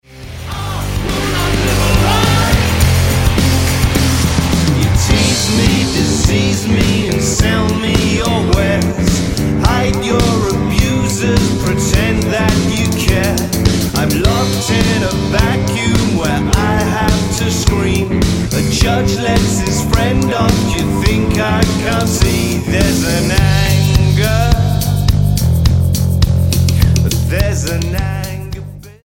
STYLE: Rock
bass
drums